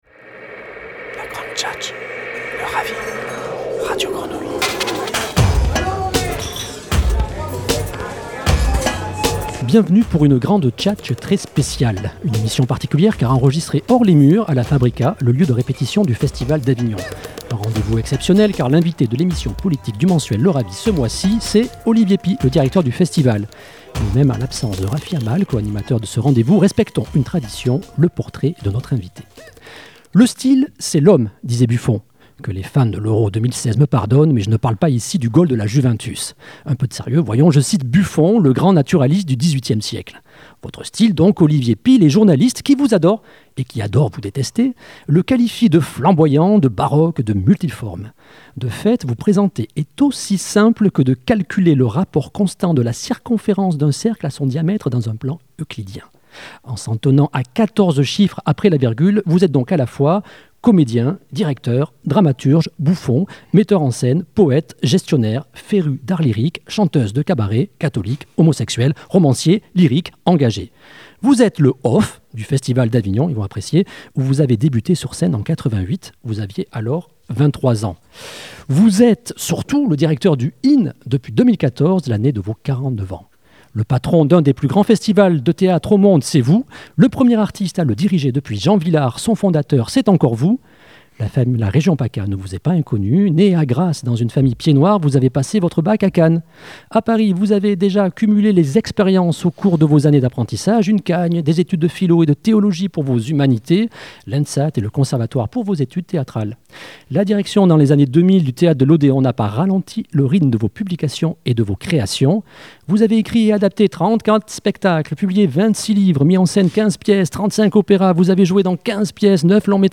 Olivier Py, directeur du Festival d'Avignon, invité de la Grande Tchatche
Entretien radio en partenariat avec Radio Grenouille